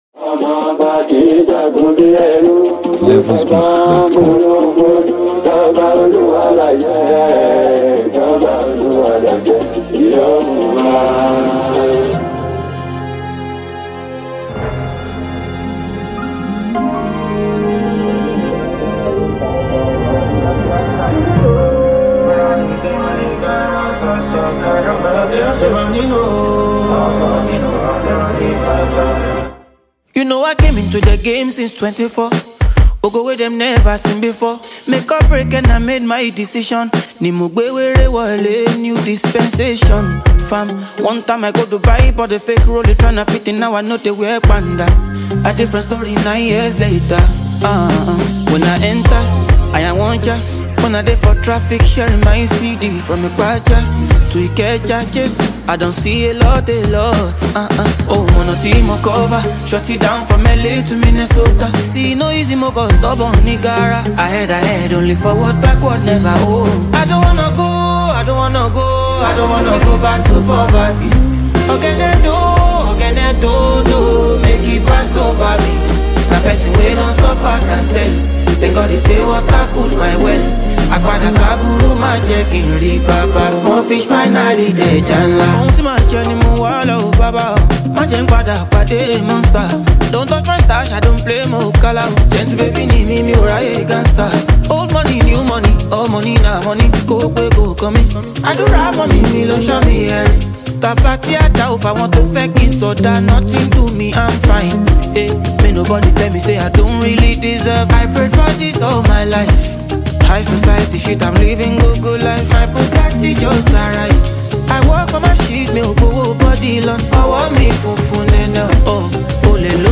bold lyrics and smooth vocals
With its catchy hook and uplifting vibe
feel-good Afrobeats energy